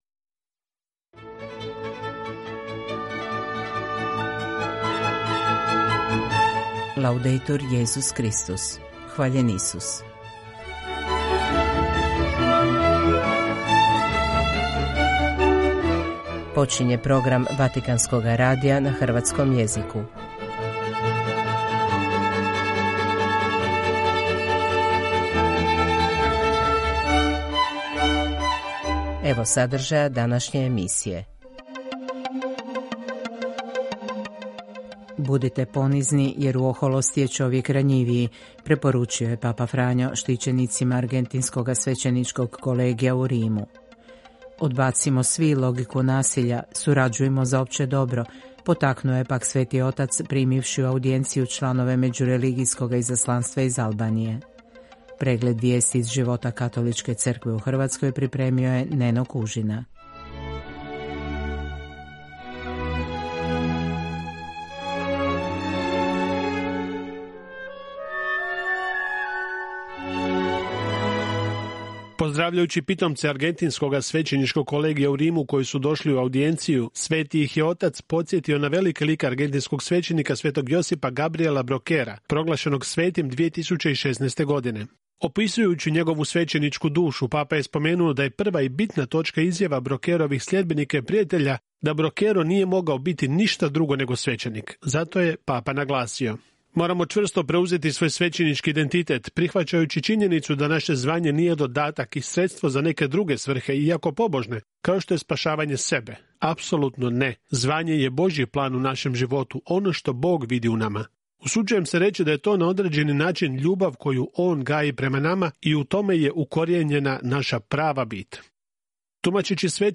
Vatican News - Hrvatski Program 214 subscribers updated 21h ago Subskrybuj Subskrybowany Odtwórz Odtwarzany Udostępnij Oznacz wszystkie jako (nie)odtworzone ...